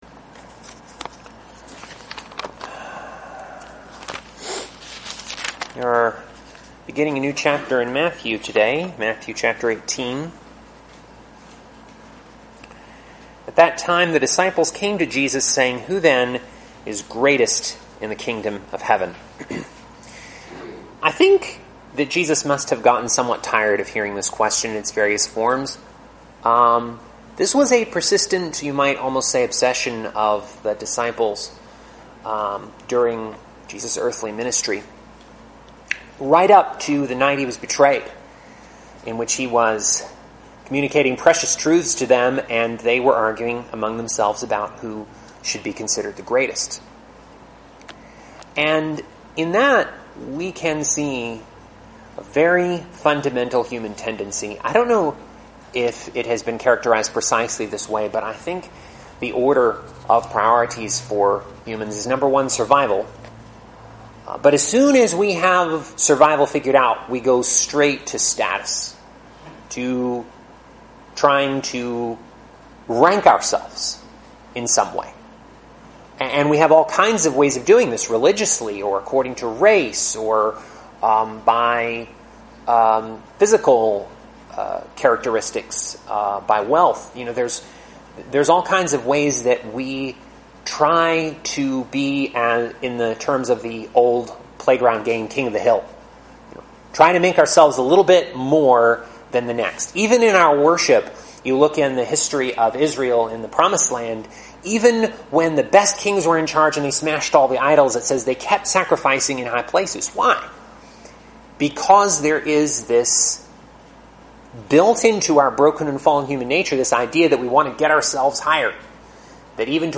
Teachings, Audio Sermons | Hebron Christian Fellowship